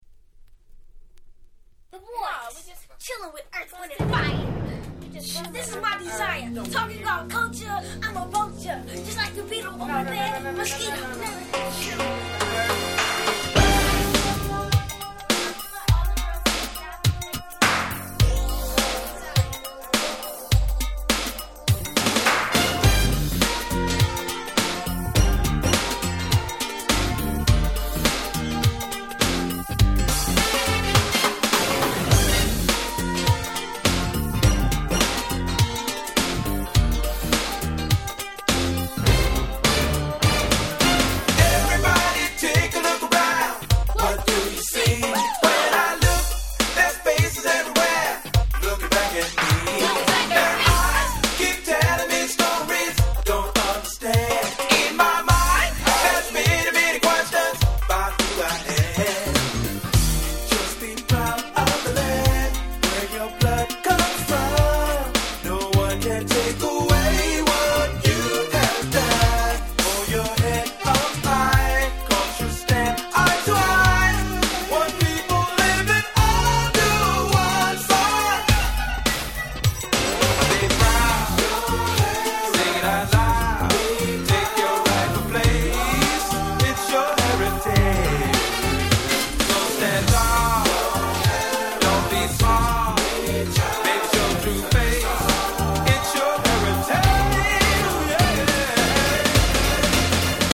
90' Nice New Jack Swing !!